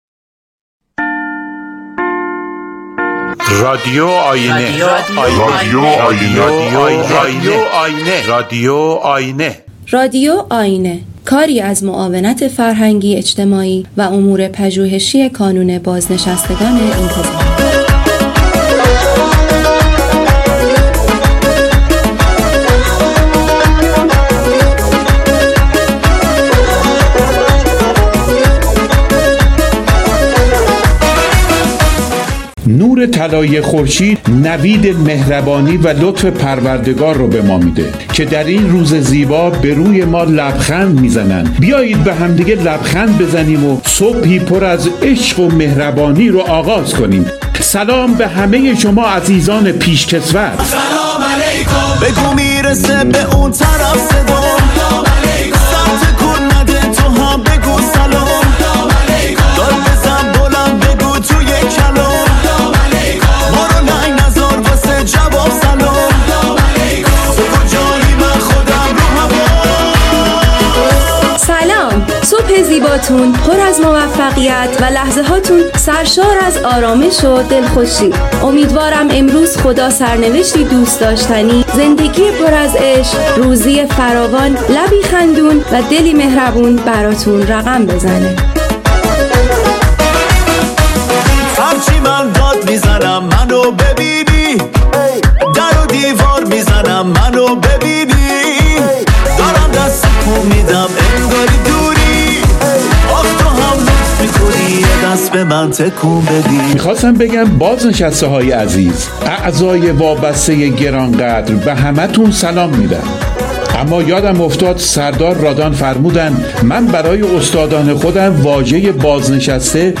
- همچنین مهمترین دغدغه بازنشستگان را در گفتگوی تعدادی از پیشکسوتان با رادیو آیینه می شنویم..